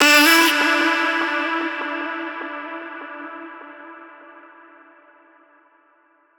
VR_vox_hit_mmhmm_E.wav